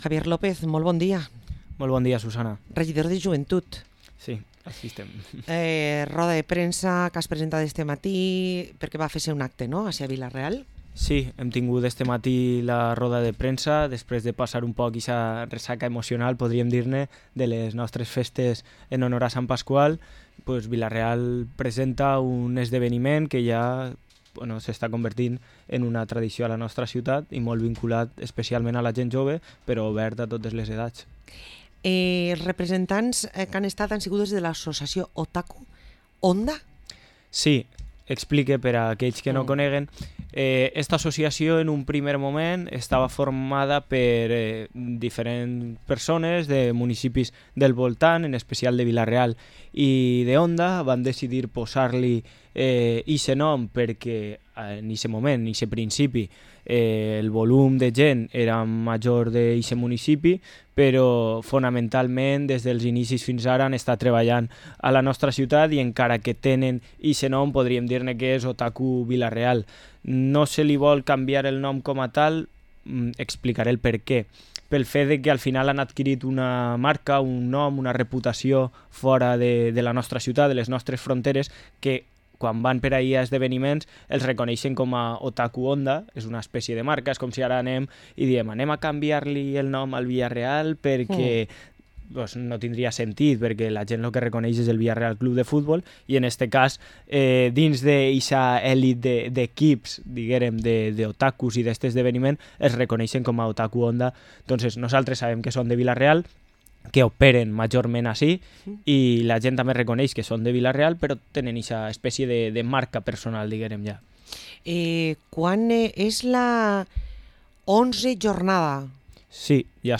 Parlem amb Javier López, regidor Joventut a l´Ajuntament de Vila-real